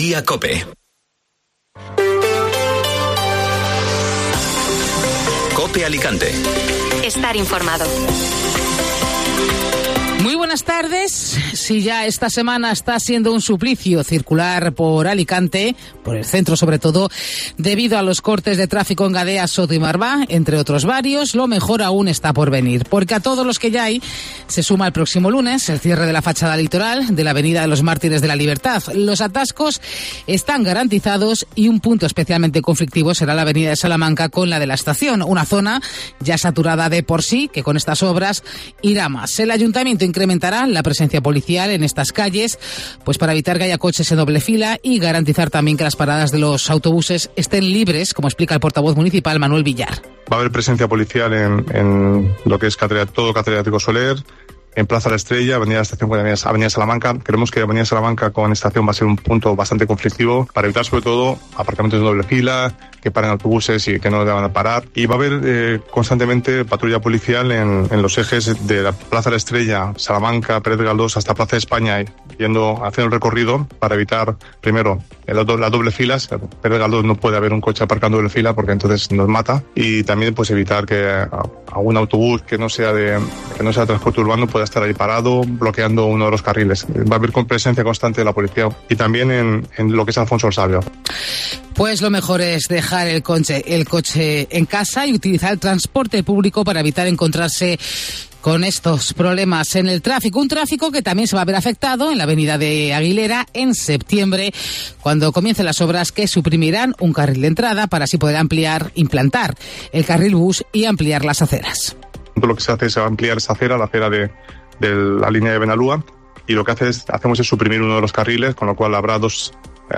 Informativo Mediodía Cope Alicante ( Martes 27 de junio)